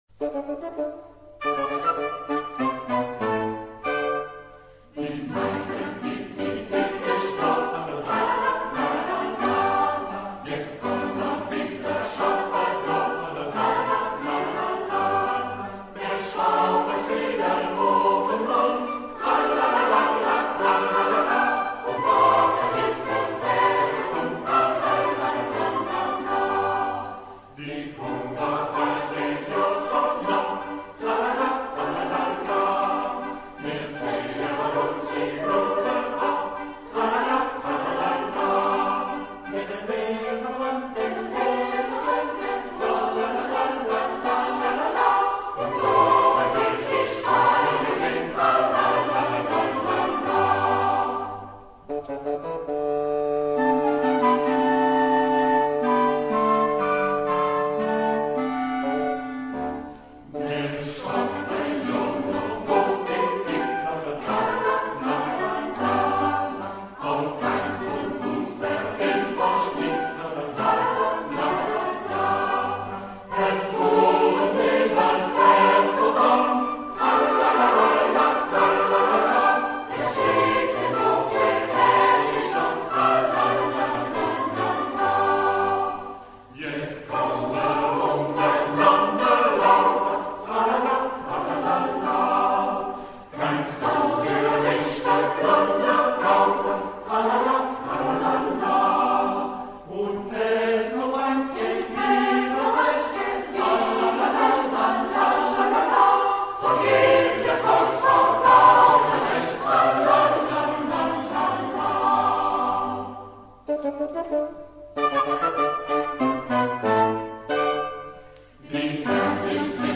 Genre-Style-Forme : Profane ; Populaire ; Chanson
Type de choeur : SATB  (4 voix mixtes )
Tonalité : sol majeur